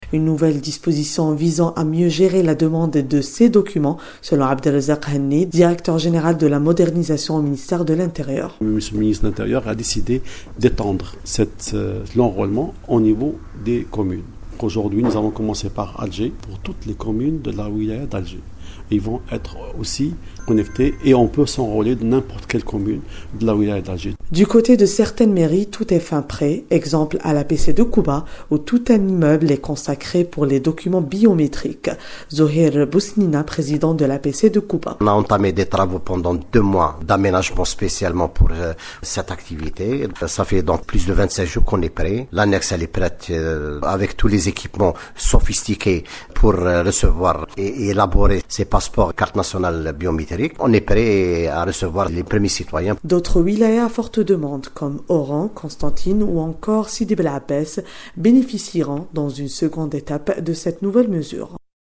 Zouhir Bousnina, P/PC de Kouba, affirme que sa commune est prête à accueillir les citoyens Déclaration de Nouredine Bedoui, ministre de l'Intérieur et des Collectivités locales.